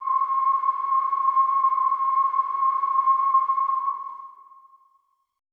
WHISTLE C -L.wav